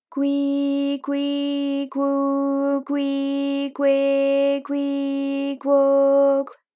ALYS-DB-001-JPN - First Japanese UTAU vocal library of ALYS.
kwi_kwi_kwu_kwi_kwe_kwi_kwo_kw.wav